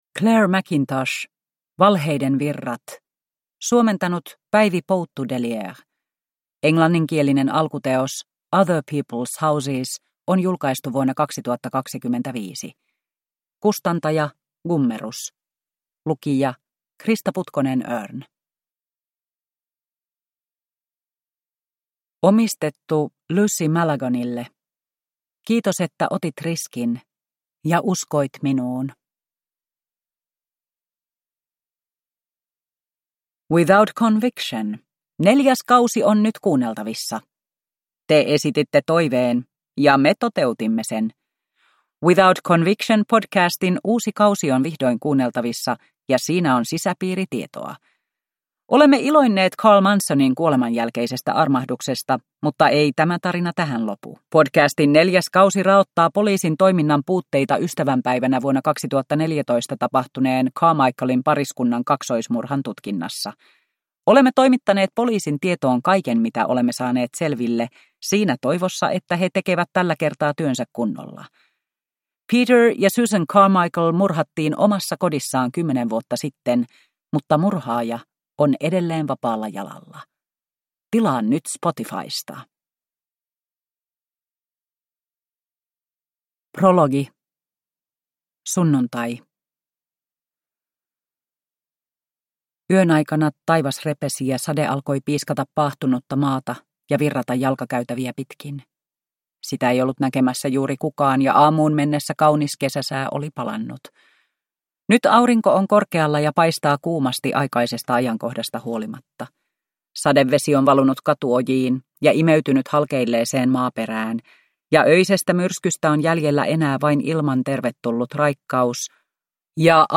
Valheiden virrat – Ljudbok